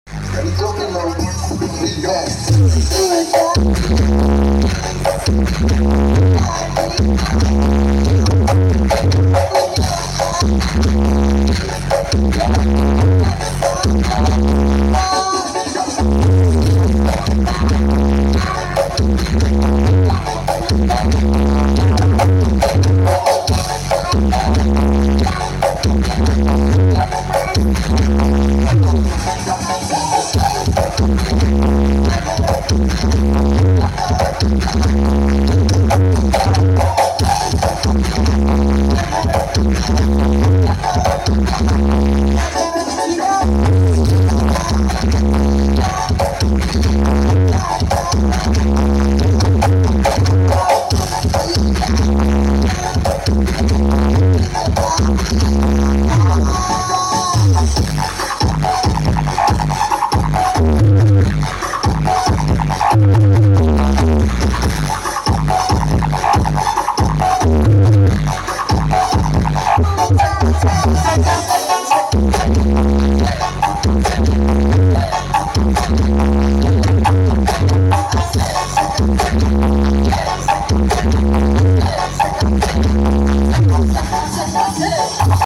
md2000 cek sound bulupitu gondanglegi